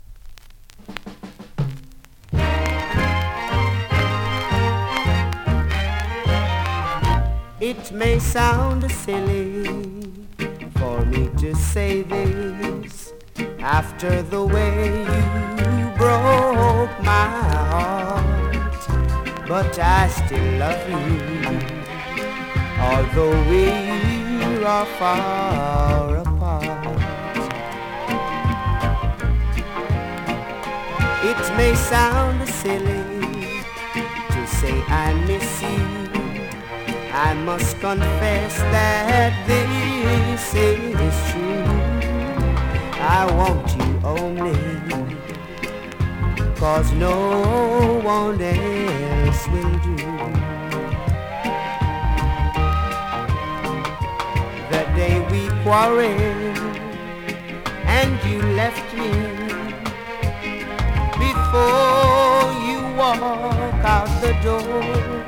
ホーム > 2018 NEW IN!!SKA〜REGGAE!!
スリキズ、ノイズ比較的少なめで